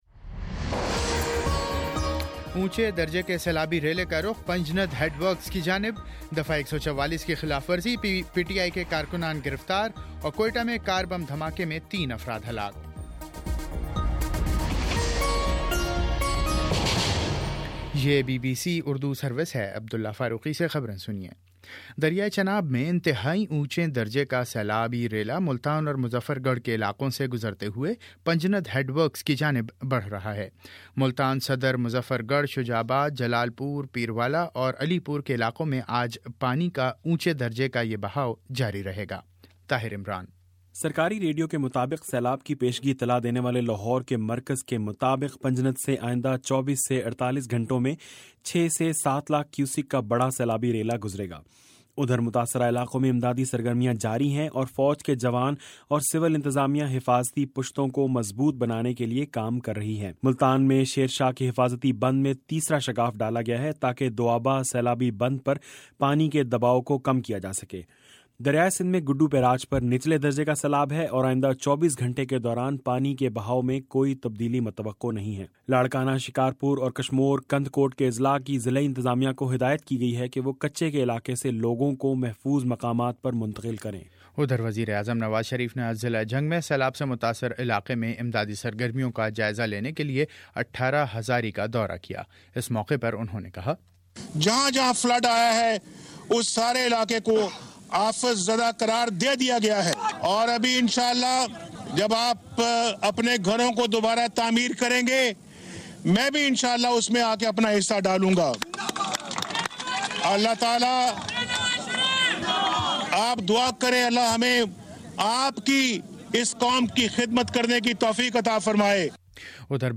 ستمبر 13 : شام چھ بجے کا نیوز بُلیٹن سات
دس منٹ کا نیوز بُلیٹن روزانہ پاکستانی وقت کے مطابق صبح 9 بجے، شام 6 بجے اور پھر 7 بجے۔